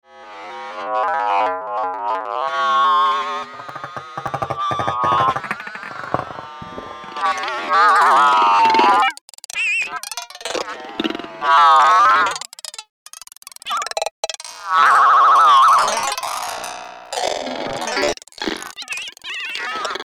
keeboard: a multi-sensor finger pressure surface
Here are three mp3 examples, where five (or more) fingers shape the sounds of two physical models of acoustic instruments (information is on the larger Max / MSP screenshot, below). one, two,